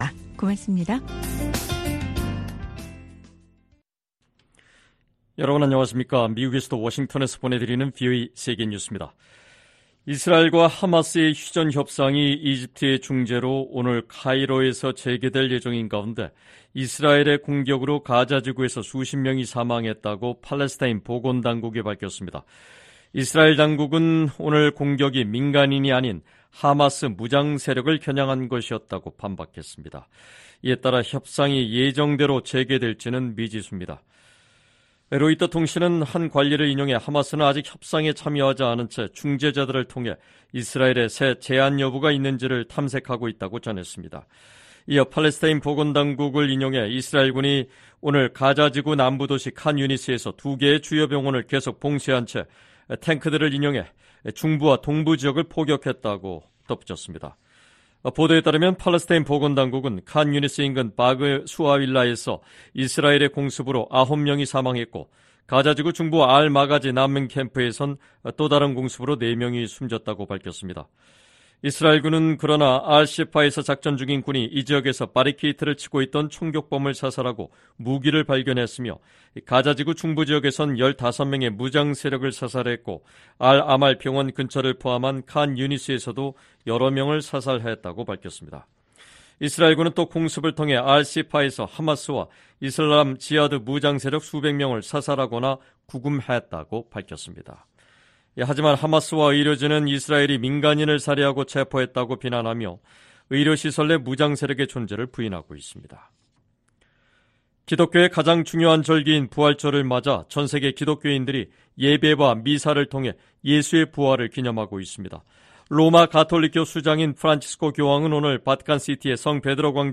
VOA 한국어 방송의 일요일 오후 프로그램 4부입니다.